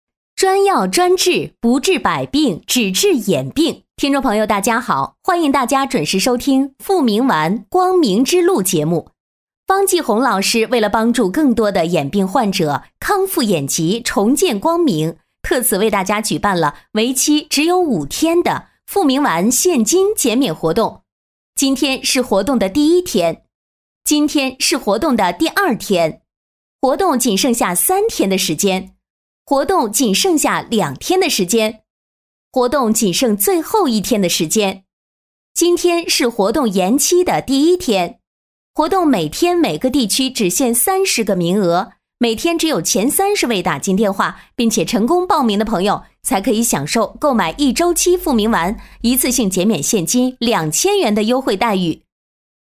医药节目女14号（自然亲切
自然诉说 医疗专题
高性价比女音，年轻、大方稳重。